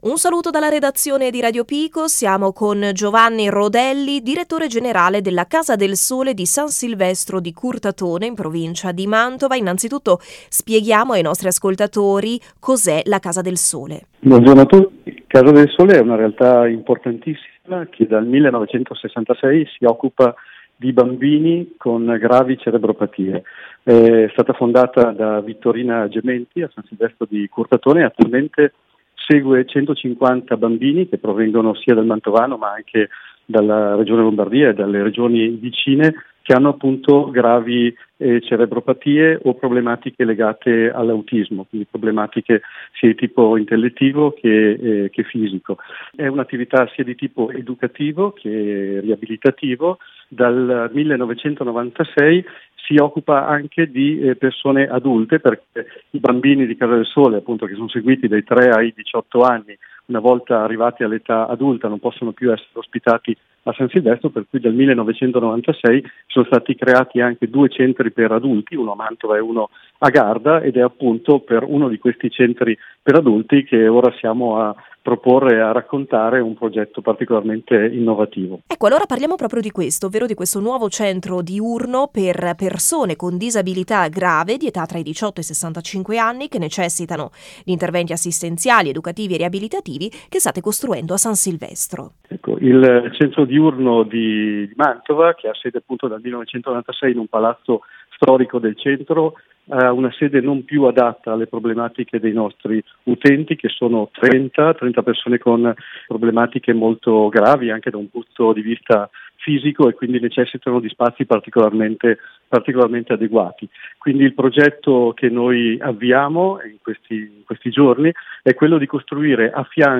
intervistare